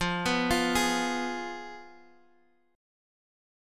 Fdim chord